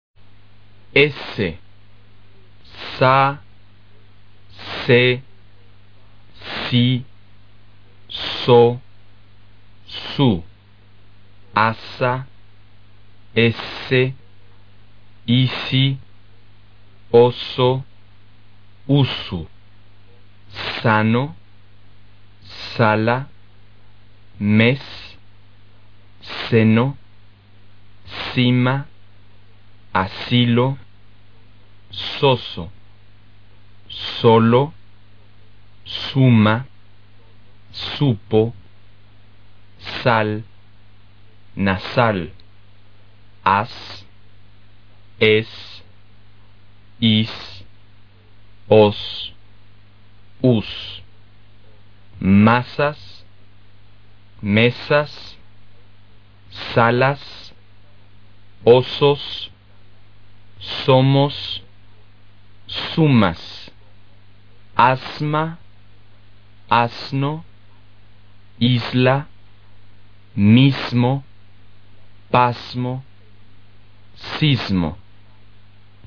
S发音】 S的名称是ese, 发音和拼音里的S一样，为清辅音，声带不振动。
当它在词尾时，发音弱而短，如汉语里面是[s]在其他浊辅音之前浊化，发[z]的音，发音方法和[s]一样，但是声带要振动，如isla, asma等等。